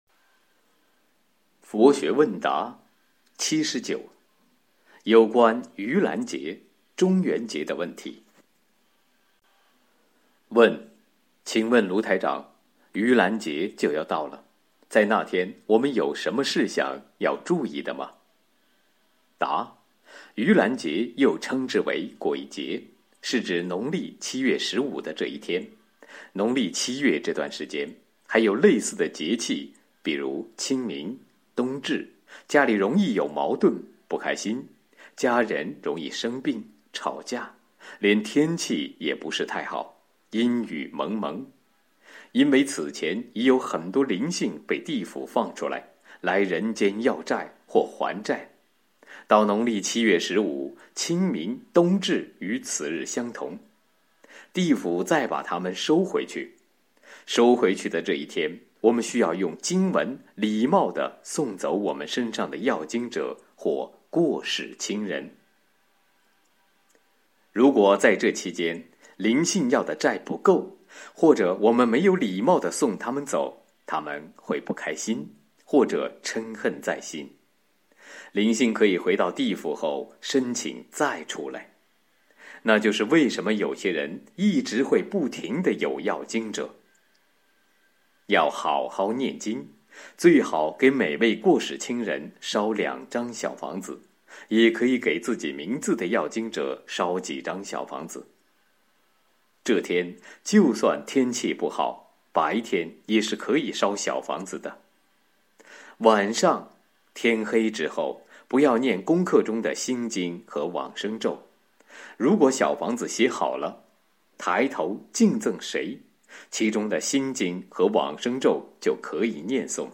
【中元节•农历七月•注意事项】2011年—2020年电台节目开示合集(53个)